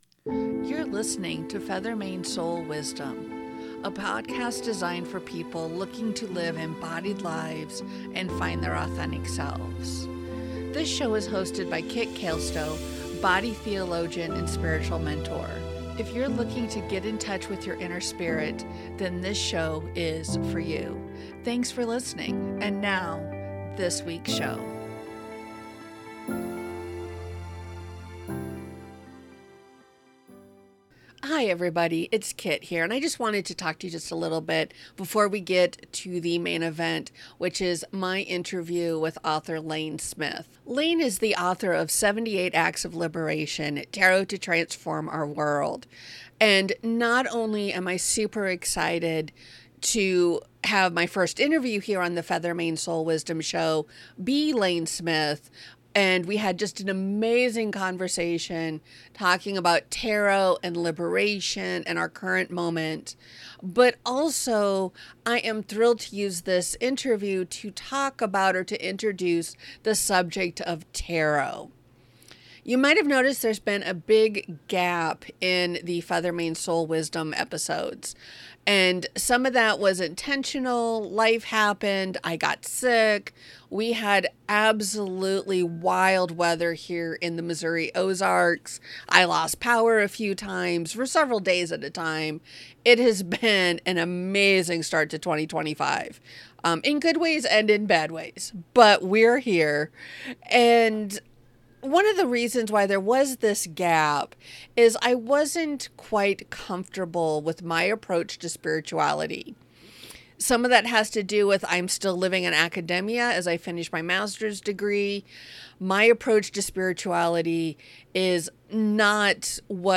Welcome to the first interview on Feathermane Soul Wisdom.